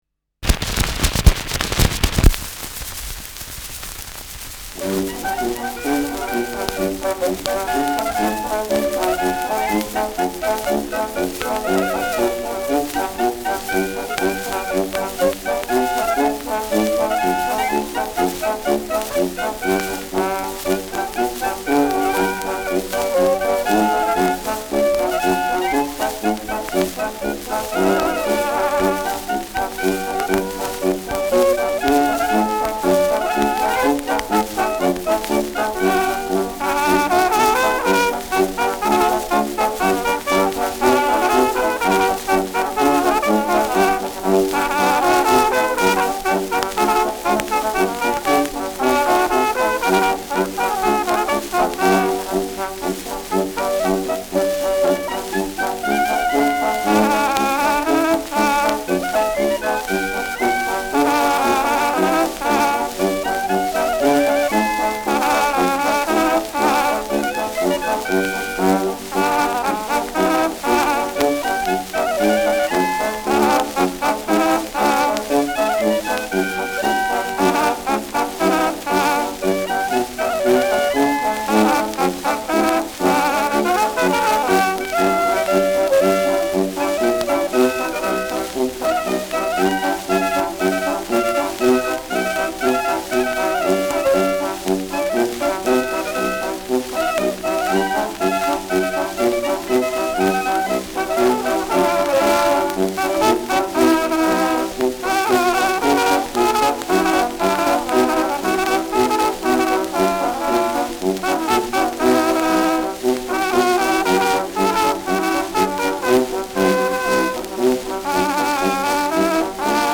Schellackplatte